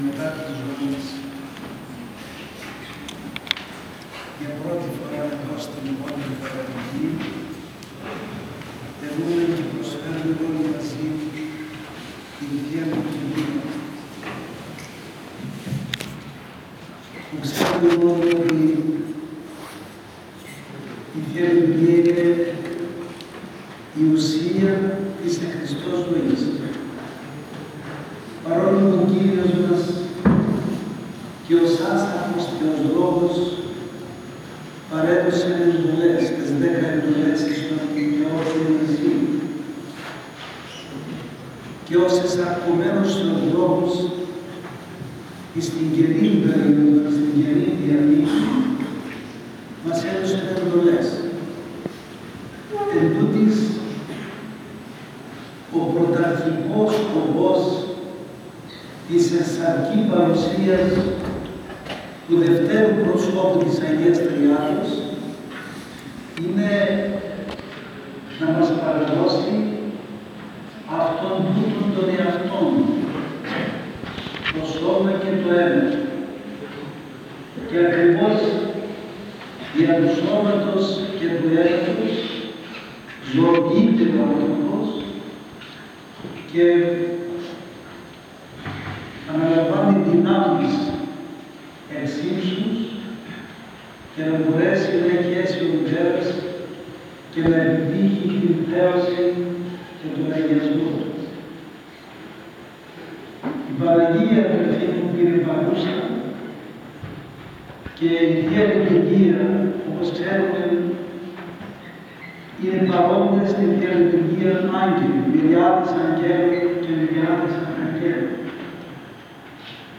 Με κατάνυξη πραγματοποιήθηκε το βράδυ της ίδιας ημέρας ιερά αγρυπνία ενώπιον της Τιμίας Ζώνης της Υπεραγίας Θεοτόκου ιερουργούντος του Καθηγουμένου της Ιεράς Μεγίστης Μονής Βατοπαιδίου Γέροντος Εφραίμ, Βατοπαιδινών πατέρων και ιερέων της Μητρόπολης Κωνσταντίας και Αμμοχώστου.
Ο χορός ψάλλει